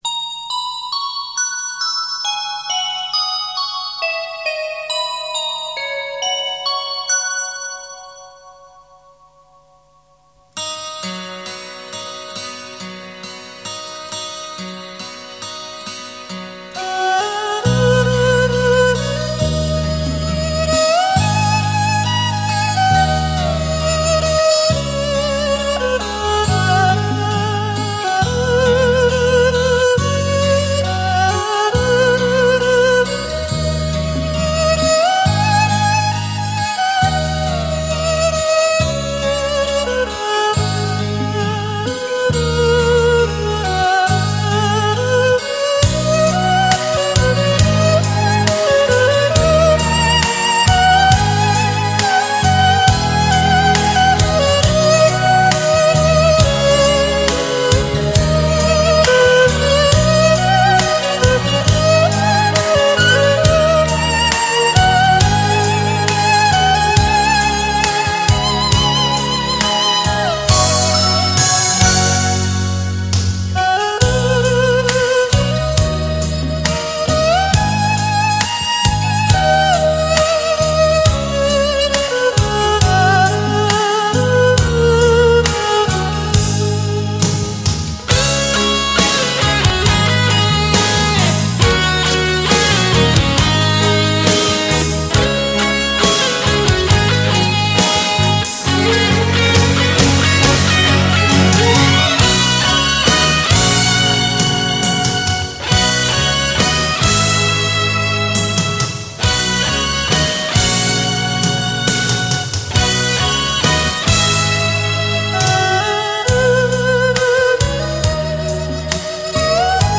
那种柔和低沉的音色时而急，时而缓，二胡那种柔中带刚的气质感受[她的古典美]